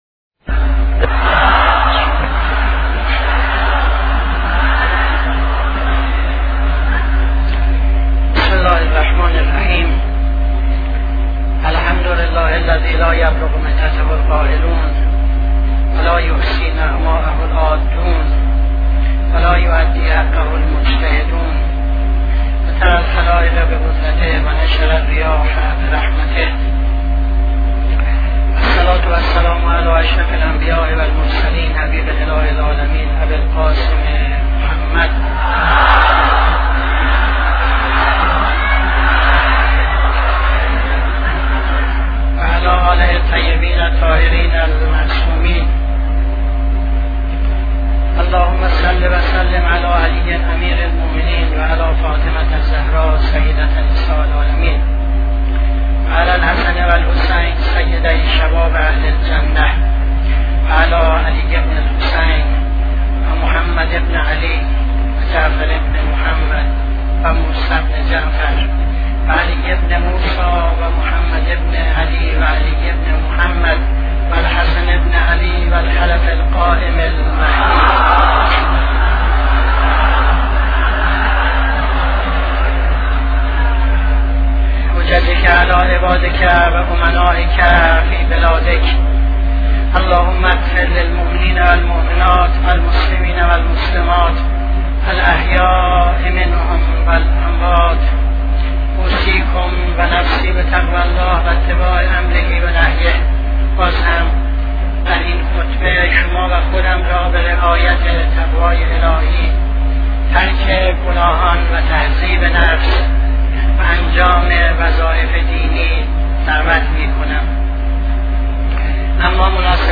خطبه دوم نماز جمعه 07-09-76